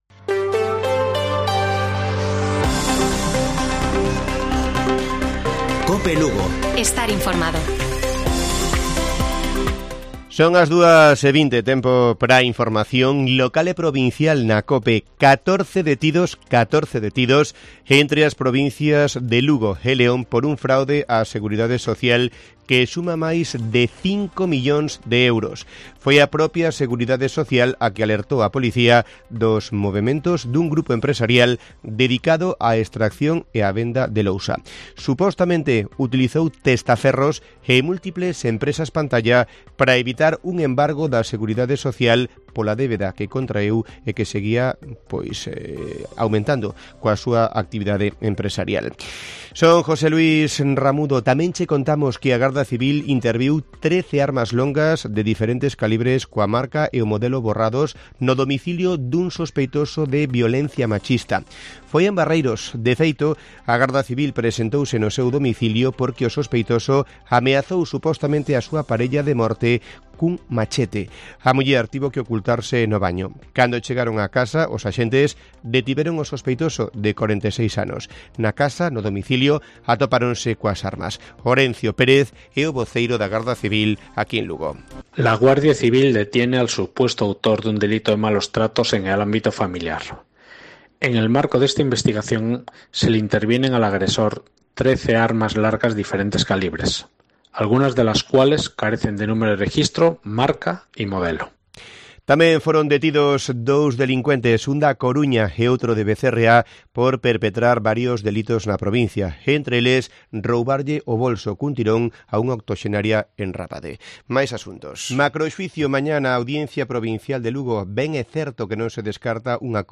Informativo Mediodía de Cope Lugo. 26 de julio. 14:20 horas